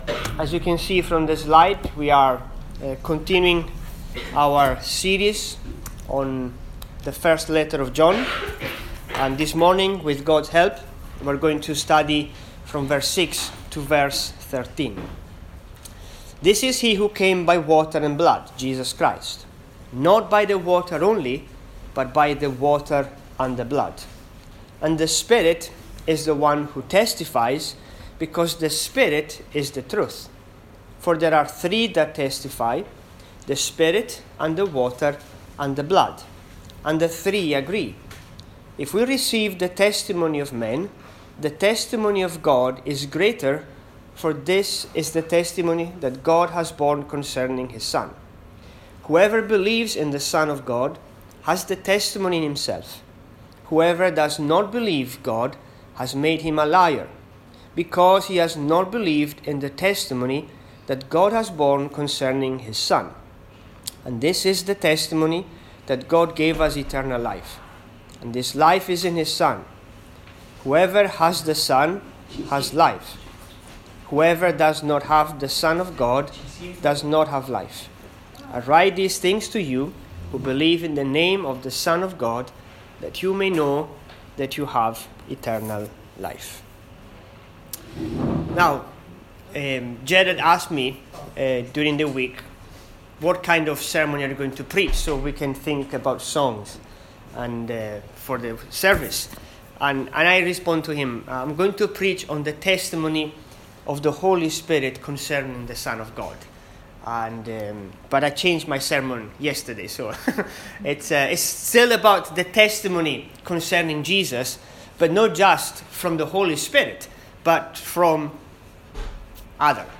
Service Type: English